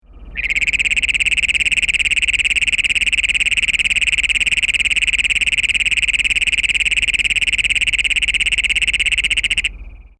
Great Plains Toad
speaker icon More Movie Icon     / A M J J A S       An extremely loud explosive jackhammer-like trill lasting from 5 seconds to almost a minute.